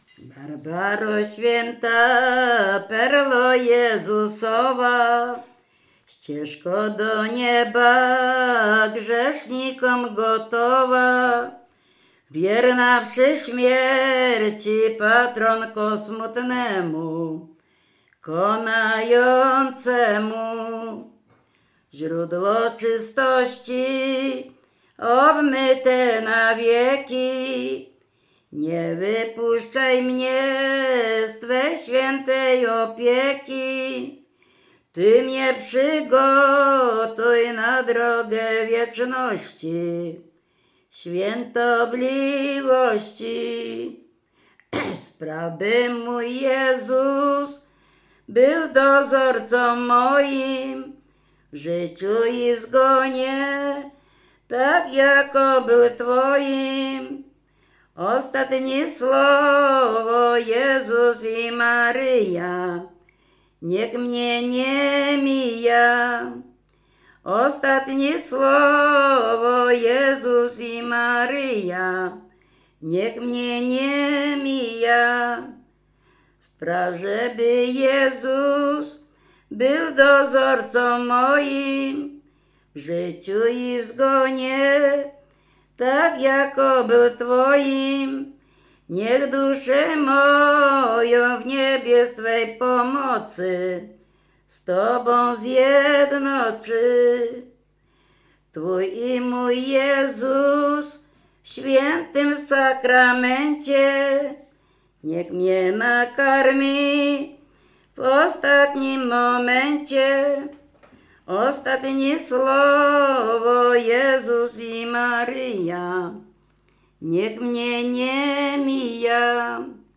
W wymowie Ł wymawiane jako przedniojęzykowo-zębowe;
Kolęda
pogrzebowe nabożne katolickie do grobu o świętych